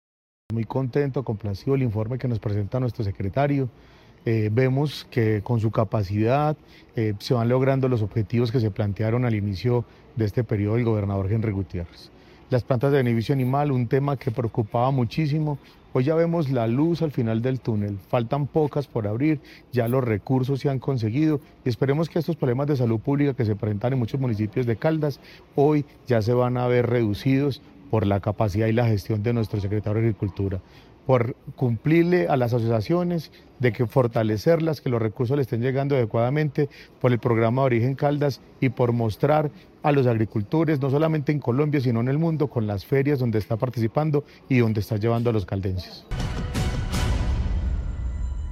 John Freddy Arenas Monsalve, diputado de Caldas.